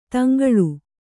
♪ taŋgaḷu